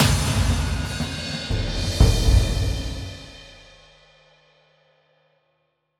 Big Drum Hit 22.wav